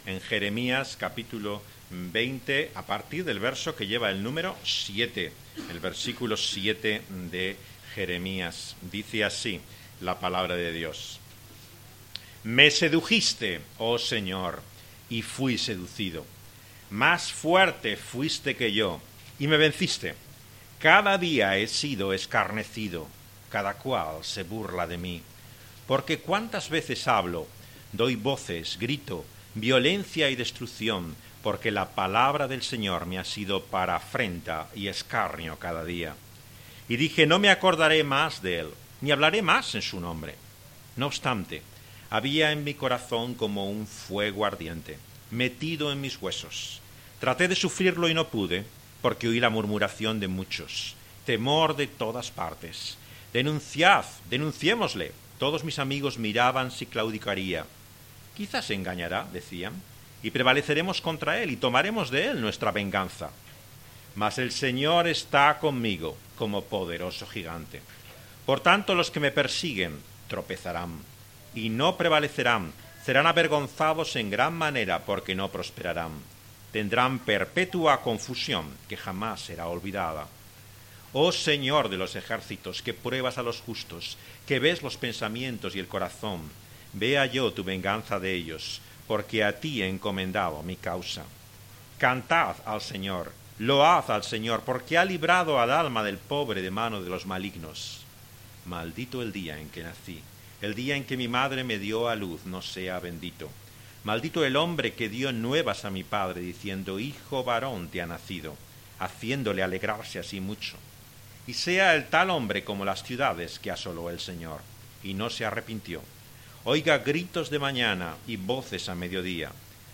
grabado en Madrid